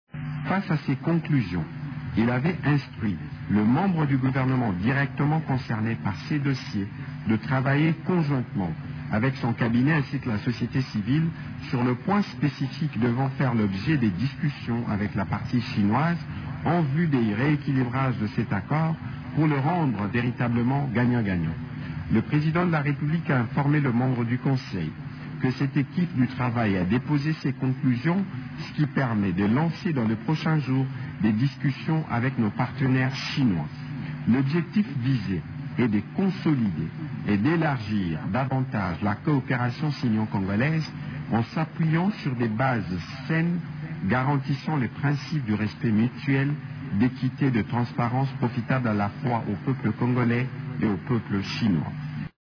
Le ministre le porte-parole du gouvernement, Patrick Muyaya, estime que ces conclusions permettent de lancer les discussions entre les deux parties à l’accord de collaboration pour le rendre véritablement gagnant-gagnant :